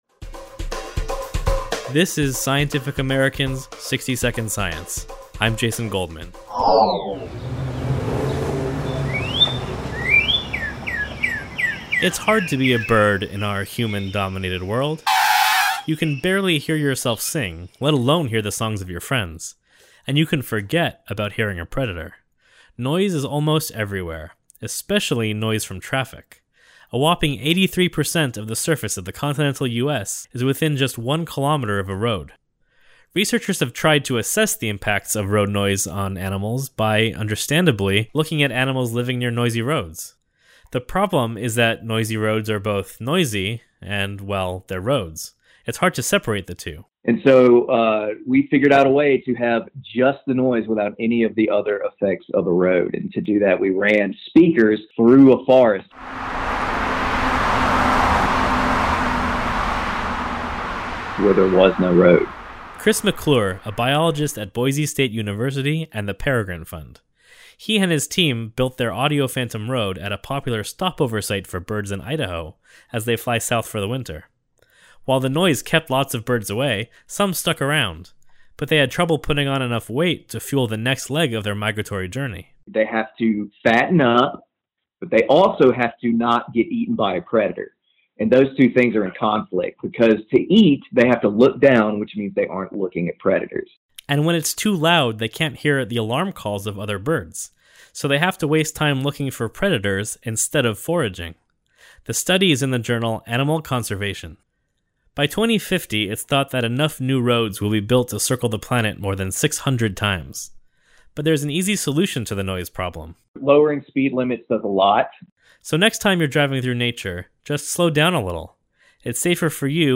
[Songbird and traffic noise]